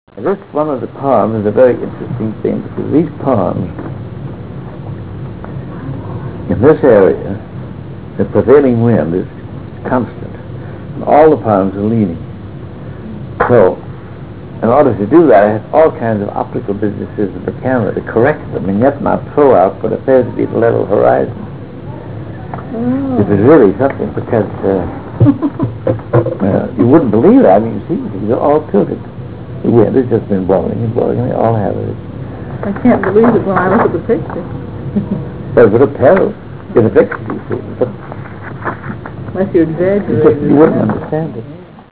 336Kb Ulaw Soundfile Hear Ansel Adams discuss this photo: [336Kb Ulaw Soundfile]